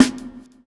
Snare (15).wav